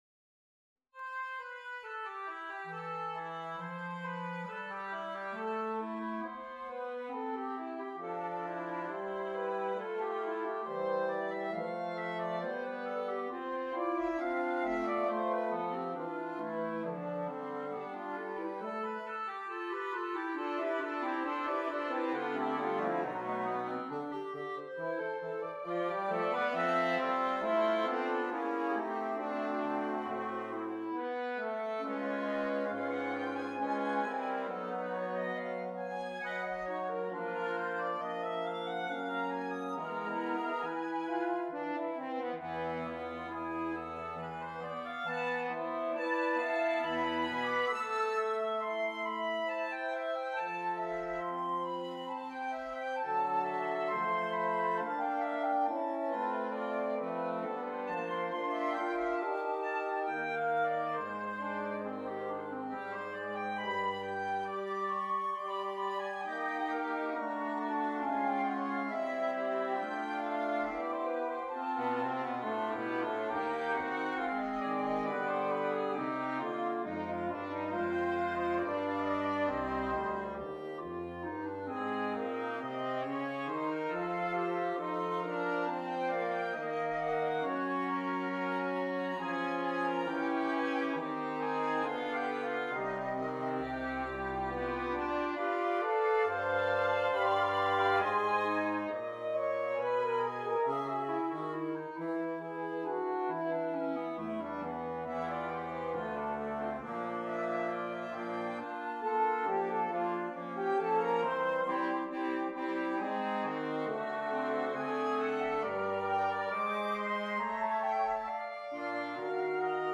Out of the Windwork borrows (all right, plunders) the initial two-bar phrase as well as four of the last five measures, then, through the use of melodic and harmonic variation, expands the piece by 50.39 percent.
woodwind quintet